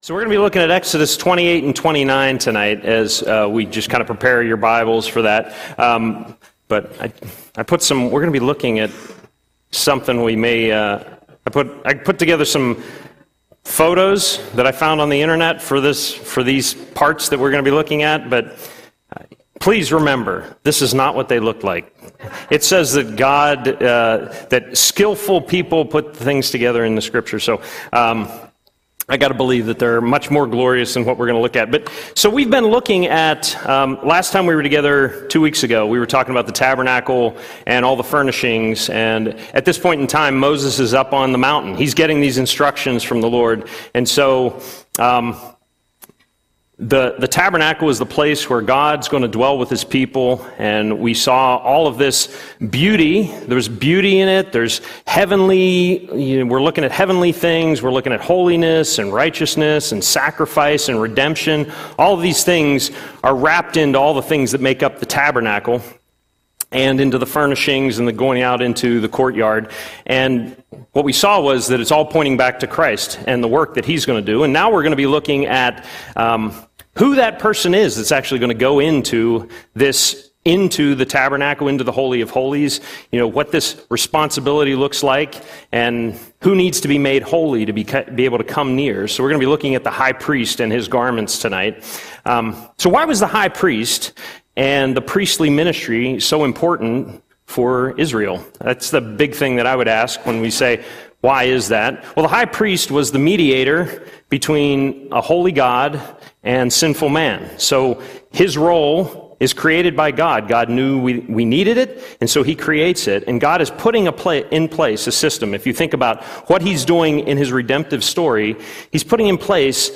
Audio Sermon - June 4, 2025